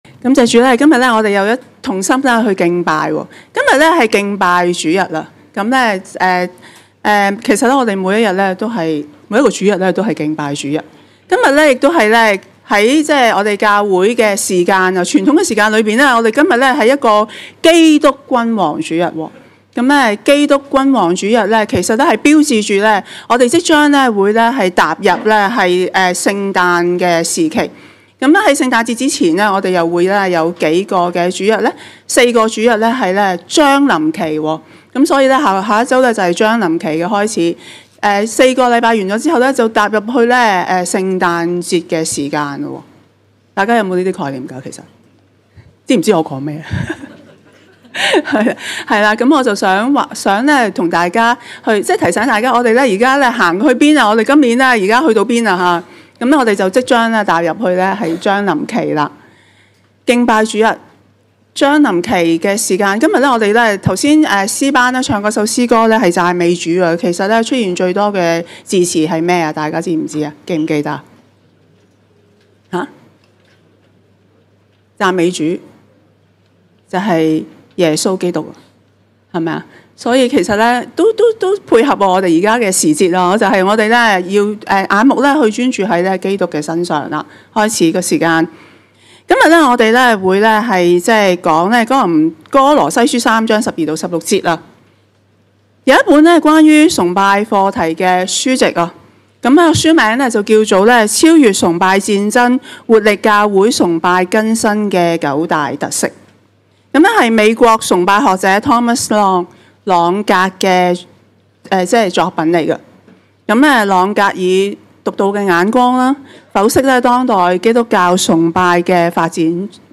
證道重溫
恩福馬鞍山堂崇拜-早、午堂